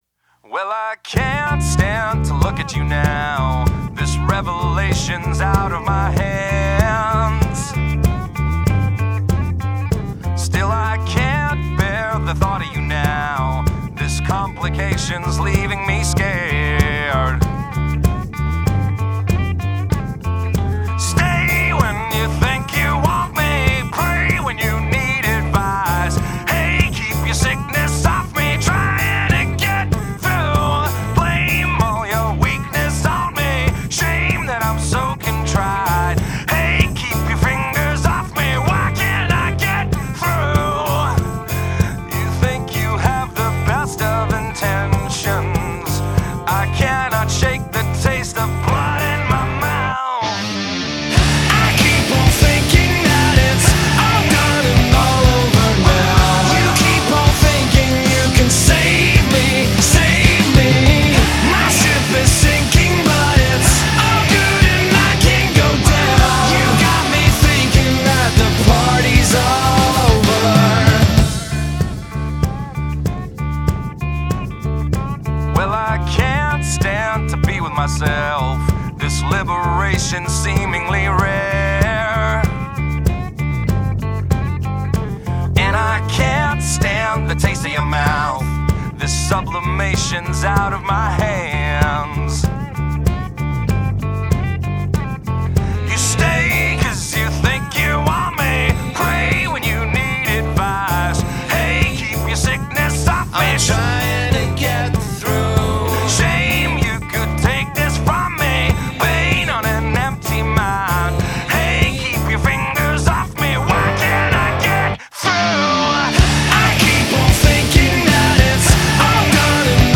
Country_Song.mp3